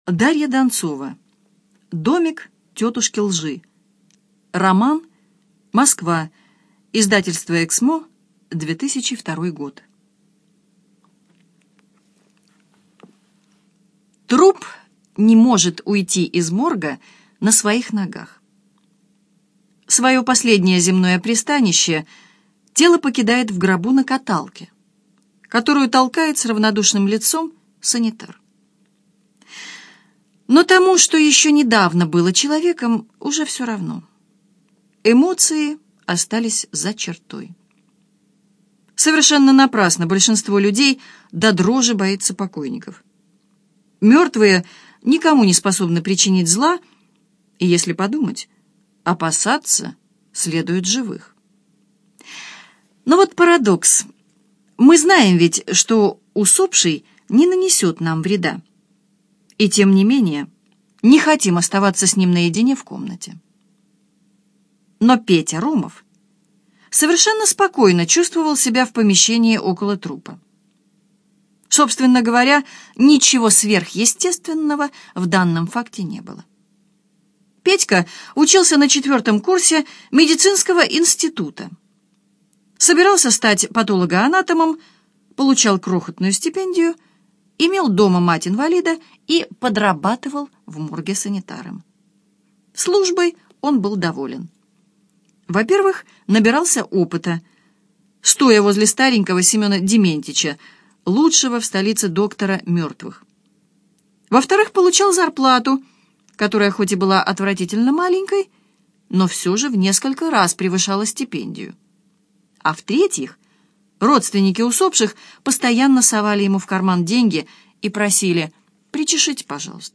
Аудиокнига Домик тетушки лжи - купить, скачать и слушать онлайн | КнигоПоиск